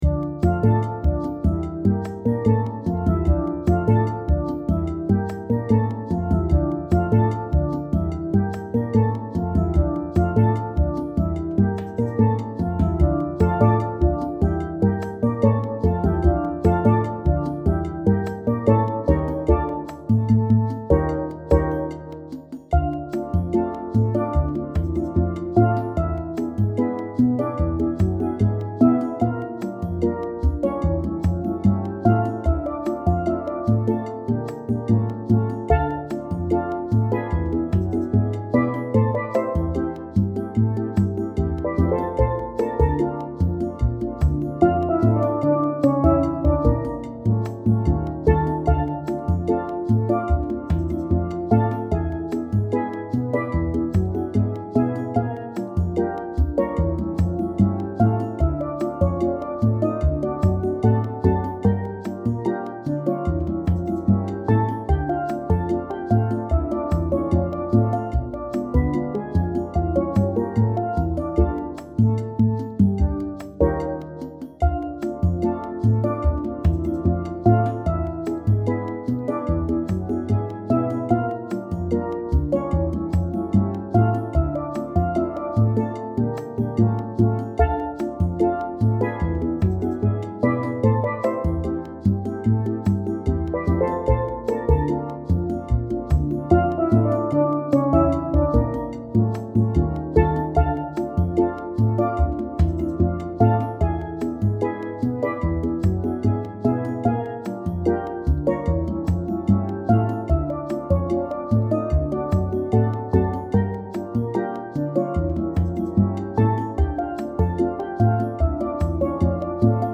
Steel Band Sound Files
These mp3's are up to tempo.